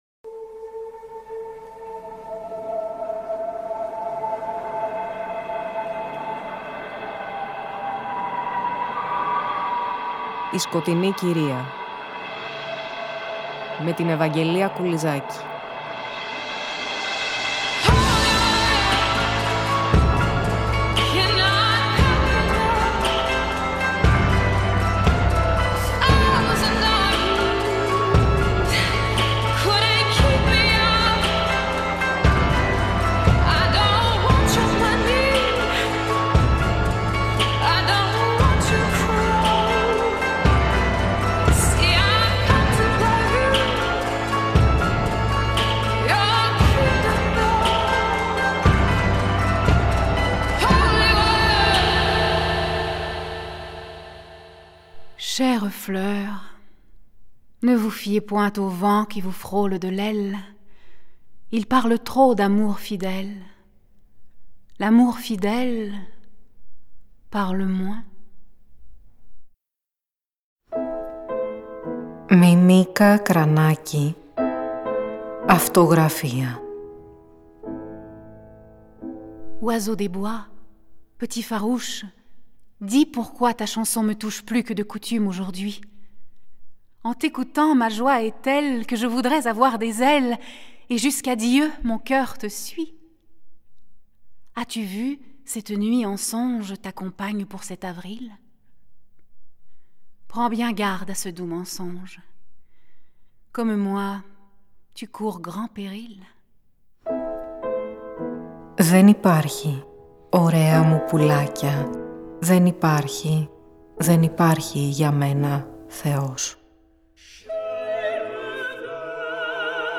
διαβάζοντας αποσπάσματα απ’ το έργο της «Αυτογραφία».